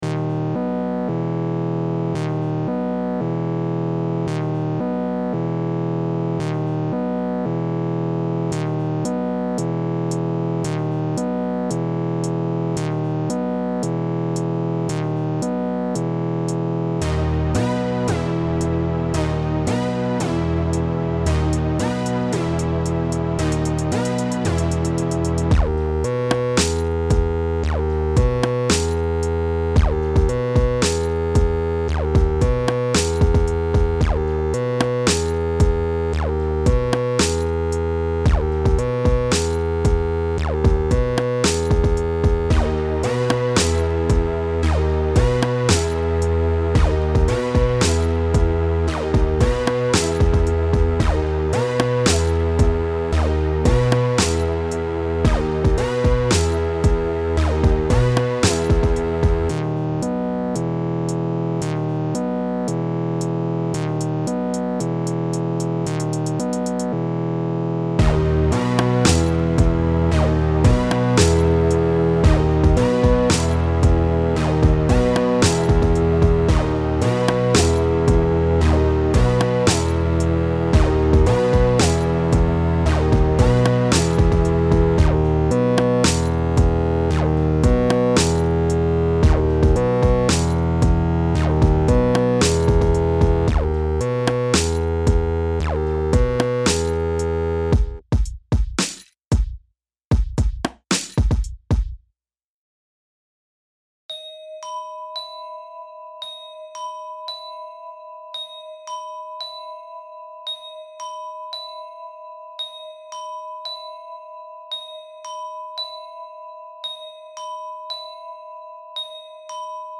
this music I made for an animation
Synth Madness.mp3